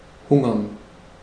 Ääntäminen
IPA: /ˈhʊŋəʁn/ IPA: [ˈhʊŋɐn]